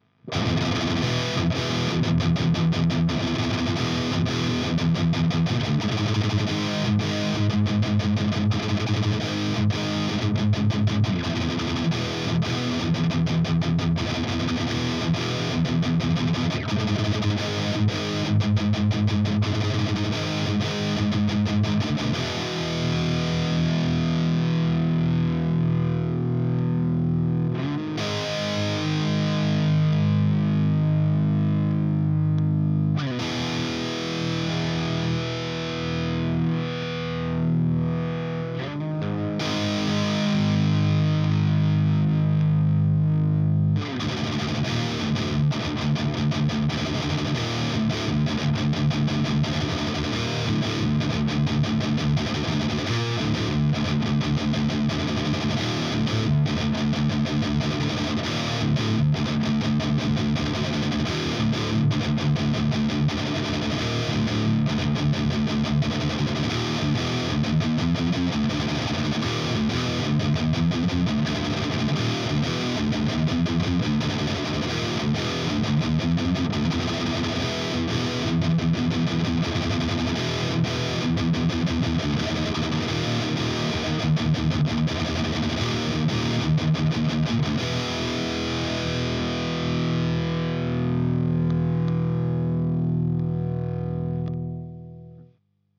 Diodes, 5U4 et GZ34 le tout dans un 57 à 8h15 du matin sans le café du matin qui va bien.
Tout les réglages de la tête sont à 12h.